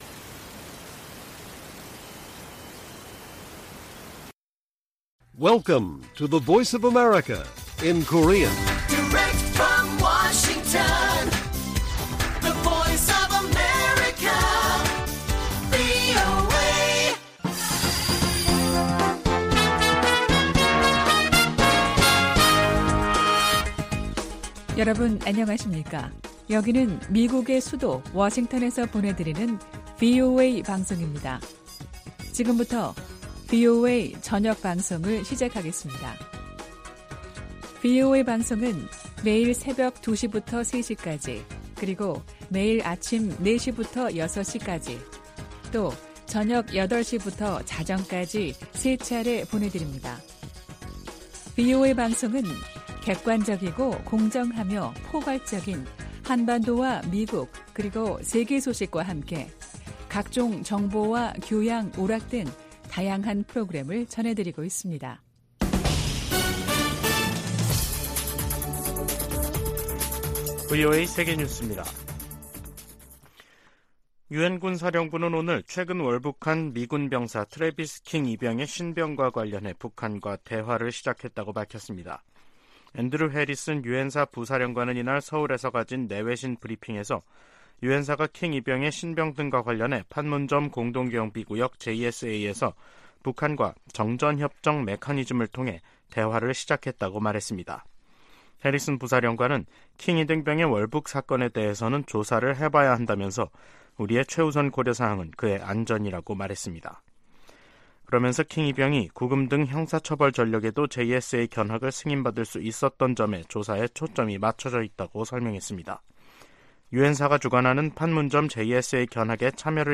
VOA 한국어 간판 뉴스 프로그램 '뉴스 투데이', 2023년 7월 24일 1부 방송입니다. 북한은 지난 19일 동해상으로 단거리 탄도미사일(SRBM) 2발을 발사한데 이어 사흘 만에 다시 서해상으로 순항미사일 수 발을 발사했습니다. 미국은 전략핵잠수함의 부산 기항이 핵무기 사용 조건에 해당된다는 북한의 위협을 가볍게 여기지 않는다고 백악관이 강조했습니다. 유엔군사령부는 월북한 주한미군의 신병과 관련해 북한과의 대화를 시작했다고 공식 확인했습니다.